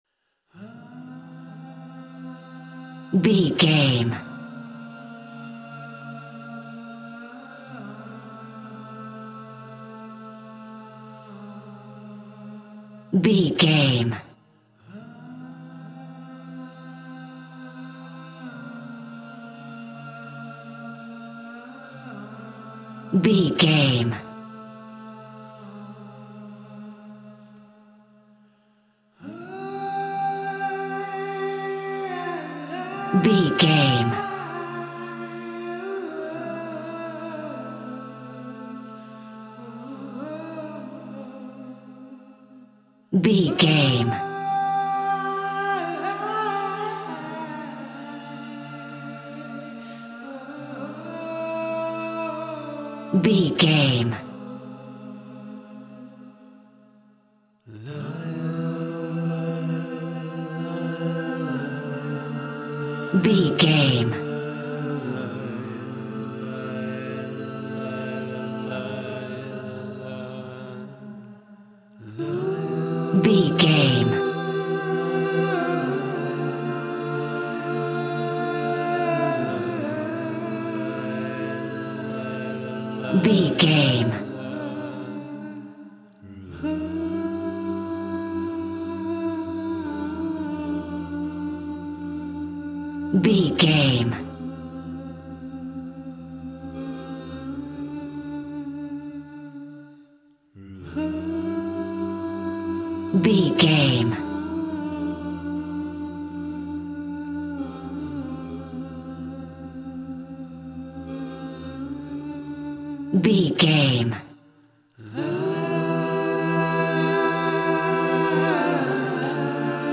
Aeolian/Minor
fun
groovy
inspirational